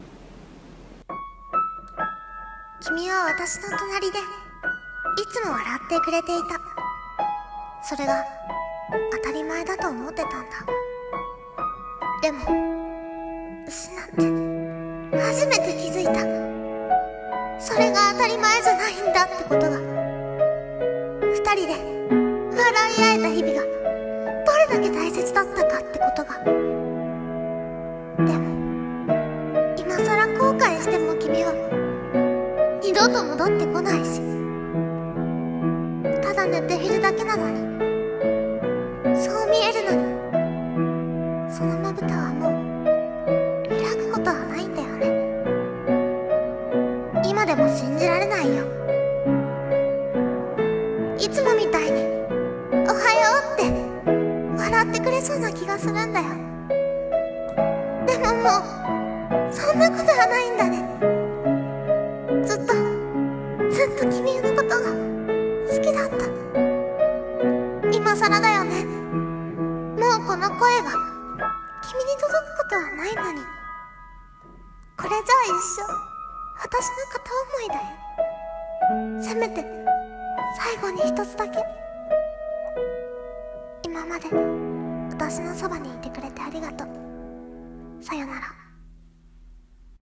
1人声劇台本